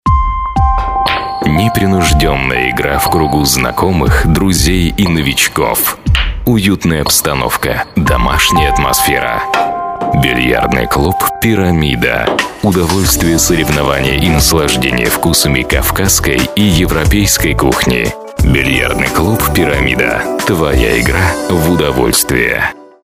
Native Russian voicetalent, narrator, presenter.
Sprechprobe: Werbung (Muttersprache):